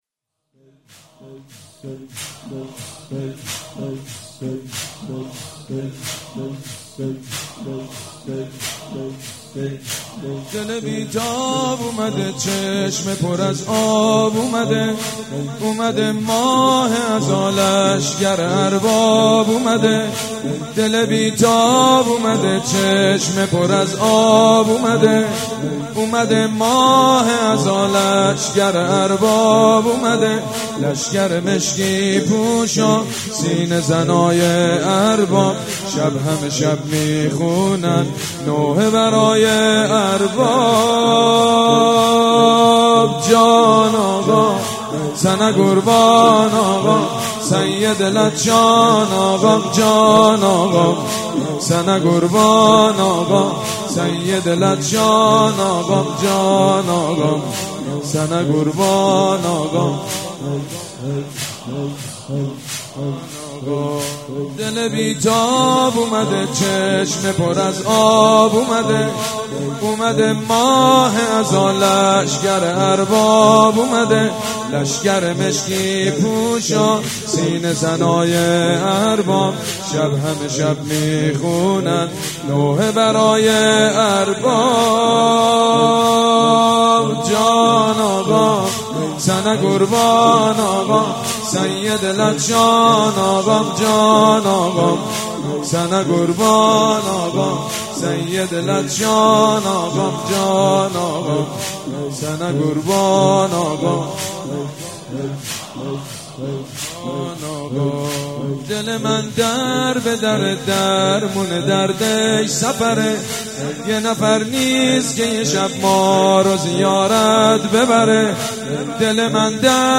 دانلود نوحه کیفیت 128